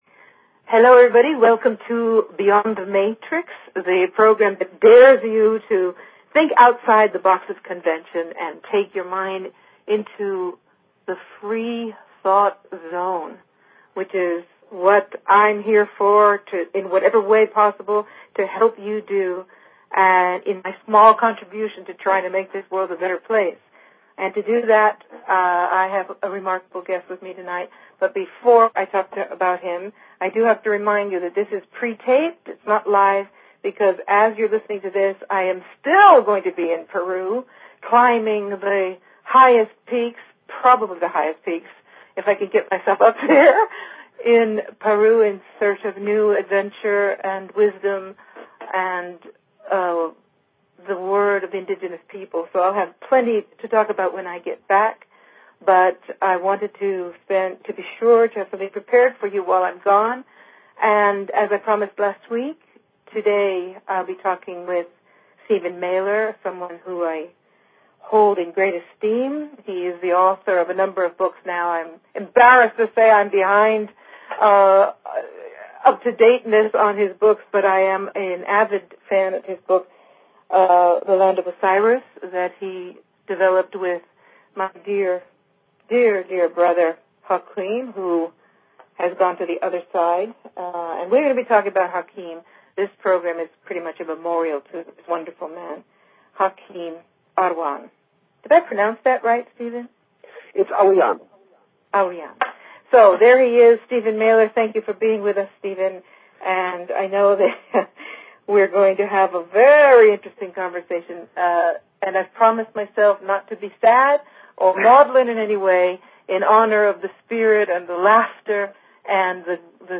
Talk Show Episode, Audio Podcast, Beyond_The_Matrix and Courtesy of BBS Radio on , show guests , about , categorized as